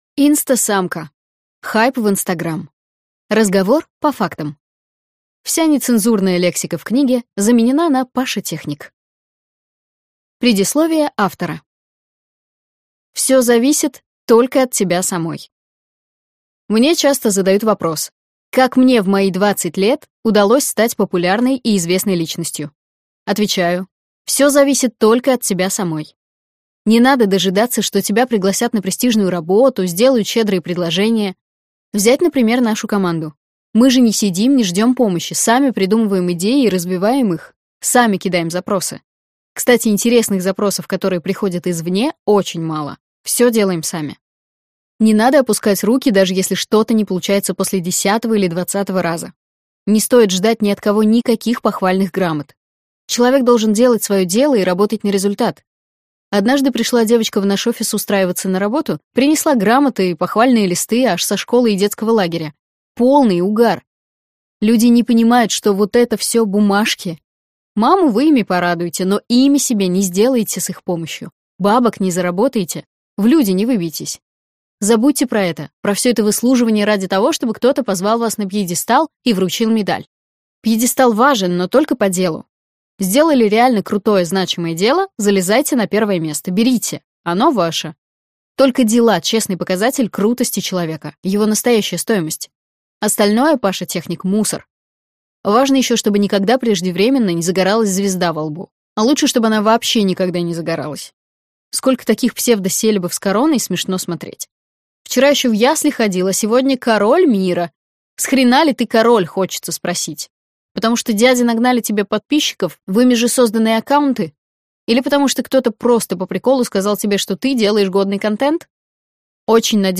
Аудиокнига Хайп в Instagram: разговор по фактам | Библиотека аудиокниг